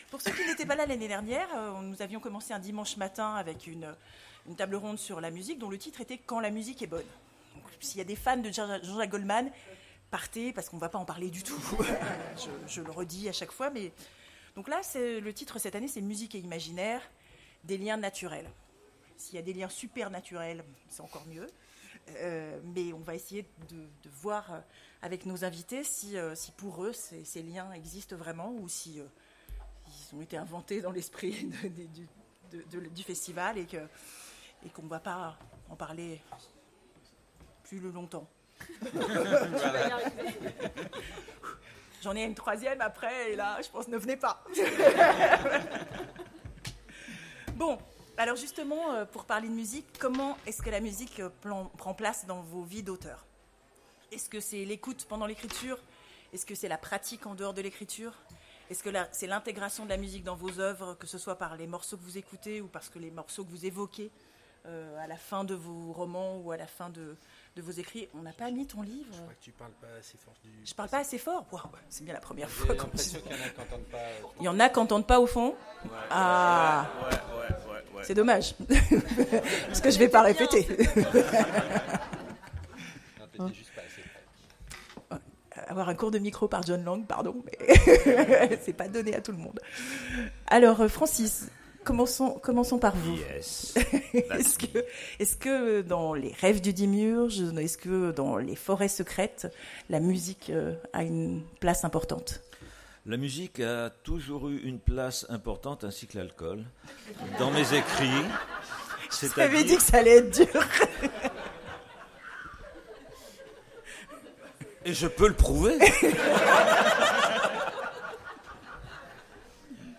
Imaginales 2016 : Conférence Musique et imaginaire